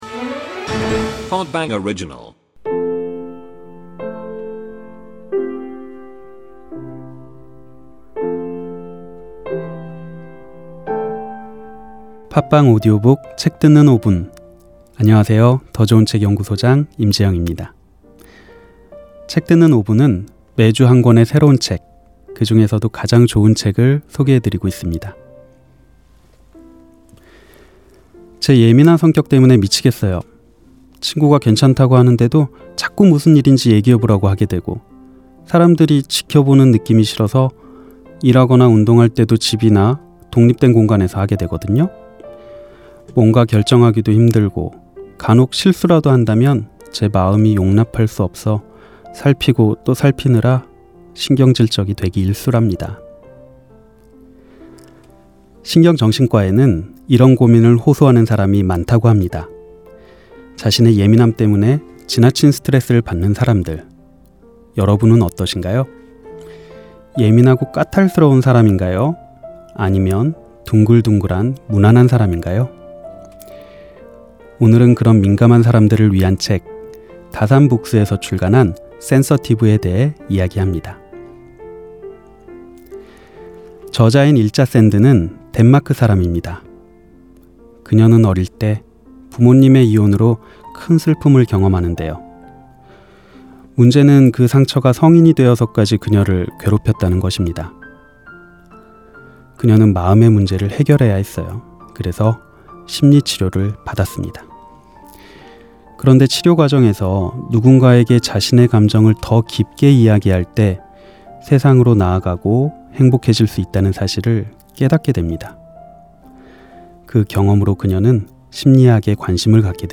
팟빵 오디오북, <책 듣는 5분>